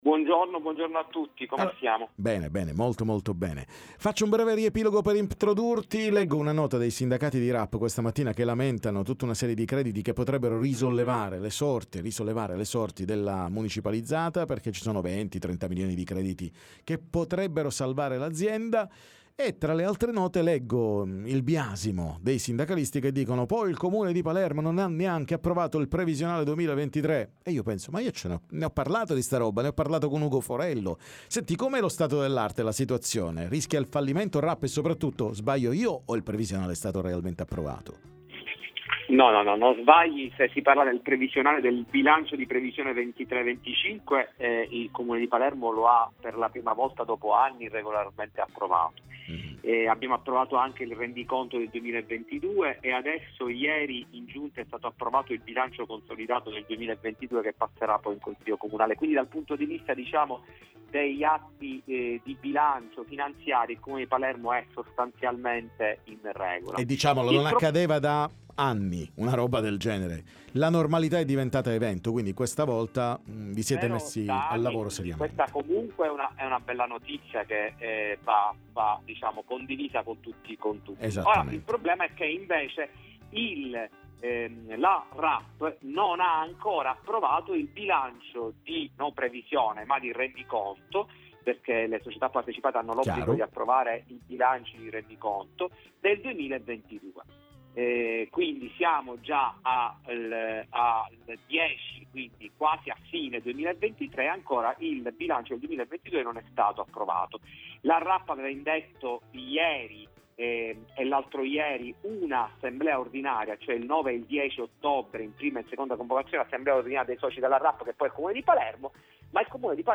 La Rap a corto di quattrini e i ritardi nella raccolta rifiuti: ne parliamo con Ugo Forello, cons. com. Gruppo Oso